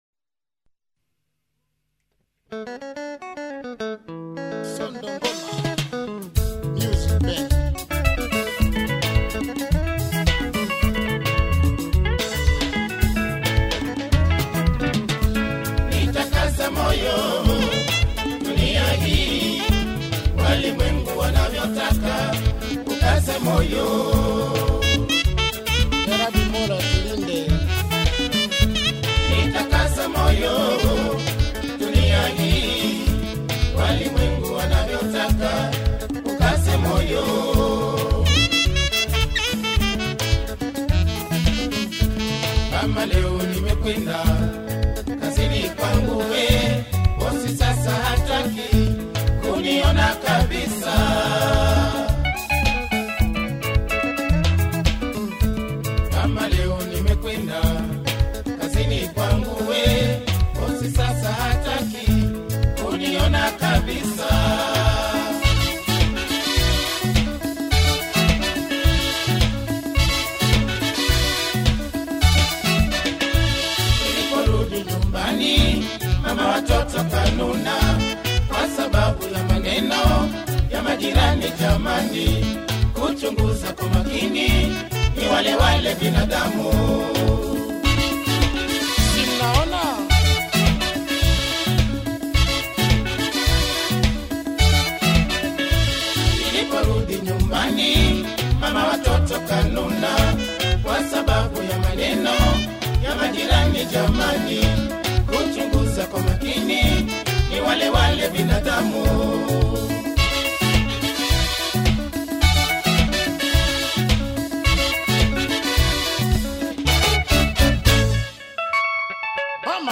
Zilipendwa / Rhumba